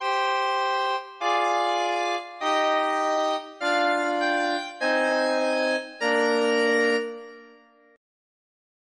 Simplicidade: tr�s exemplos complexos ou Complexidade: tr�s exemplos simples [ anterior ] [ pr�xima ] Capa Exemplo 1 Exemplo 2 Exemplo 3 [ ouvir ] O acr�scimo de ter�as acima da voz inferior, neste caso, n�o pode ser considerado como incomum, completando a defini��o tri�dica, evidentemente n�o funcional.